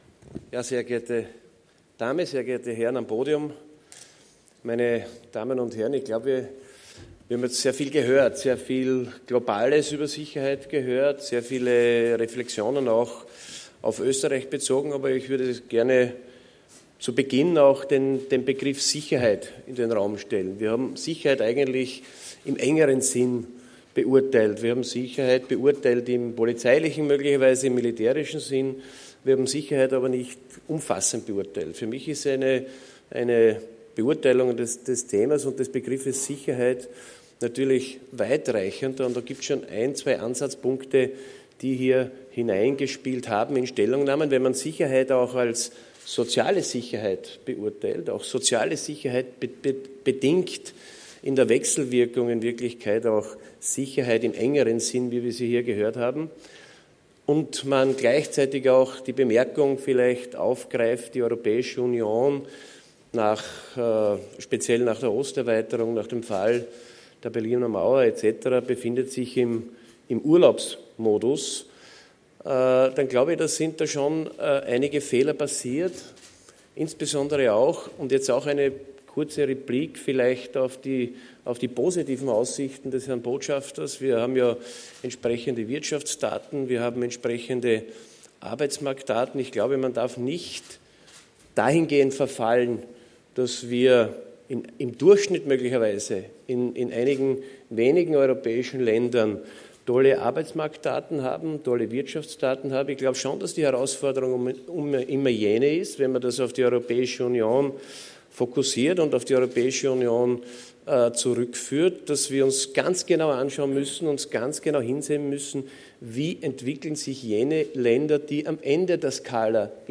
Wien, 12. Jänner 2017 - Im Rahmen des sicherheitspolitischen Jahresauftakts der Direktion für Sicherheitspolitik analysierte Verteidigungsminister Hans Peter Doskozil die derzeitigen sicherheitspolitischen Herausforderungen für die Sicherheit Österreichs - und er leitete konkrete Herausforderungen für die nächsten Monate ab.